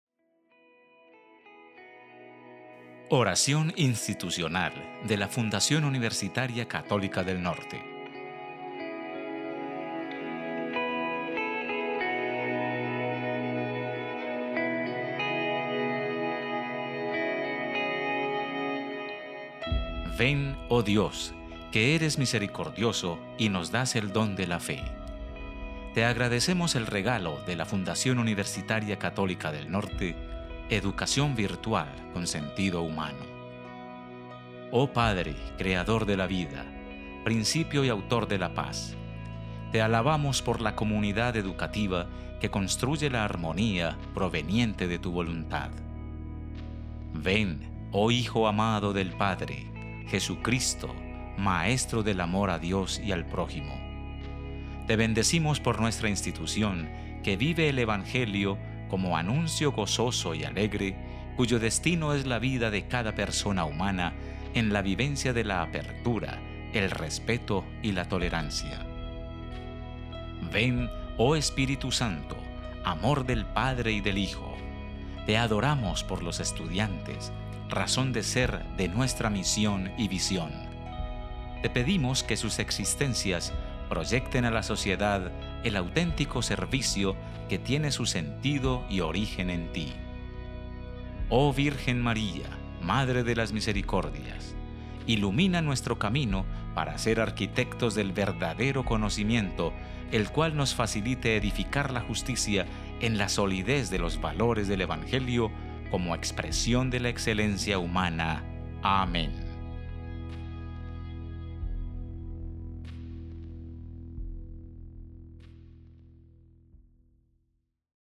Oración institucional Católica del Norte
UCN-oracion-institucional.mp3